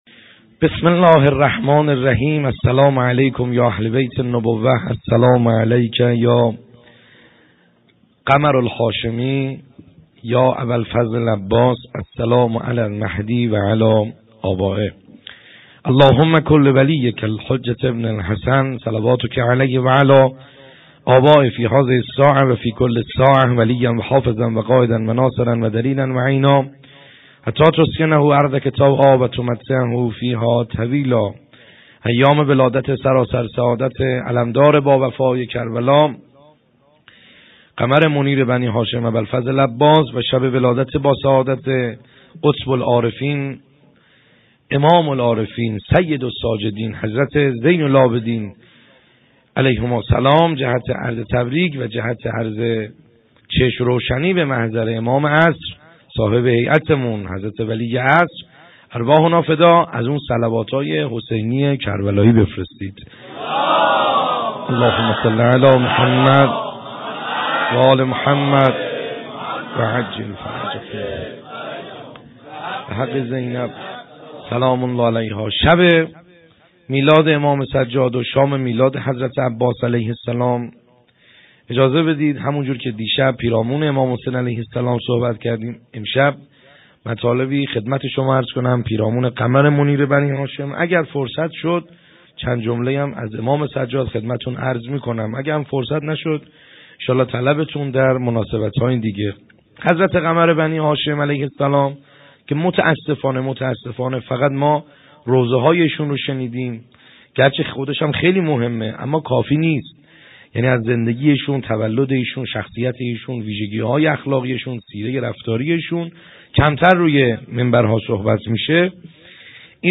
خیمه گاه - بیرق معظم محبین حضرت صاحب الزمان(عج) - سخنرانی | کیفیت موبایلی